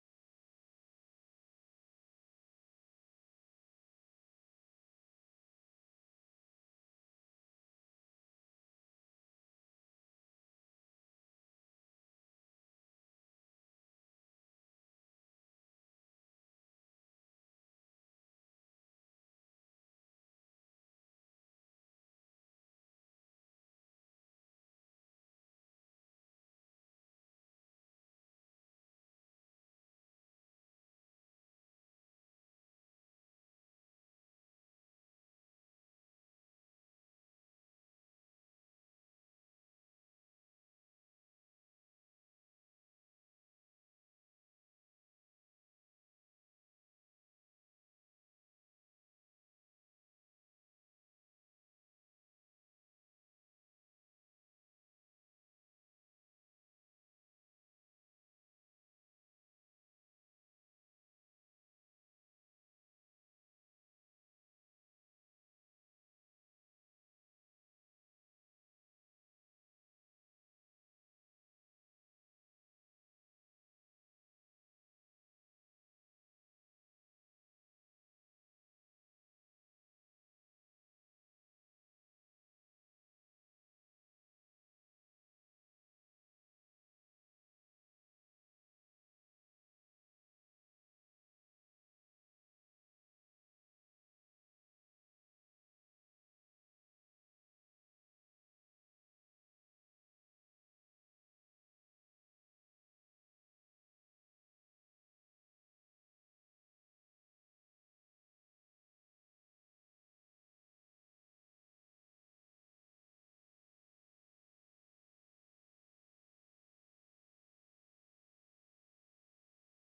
Being a Christian – Saving Faith part 2 Sermon
Being-a-Christian-Saving-Faith-part-2-Sermon-Audio-CD.mp3